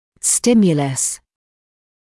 [‘stɪmjələs][‘стимйэлэс]стимул; раздражитель (мн.ч. stimuli)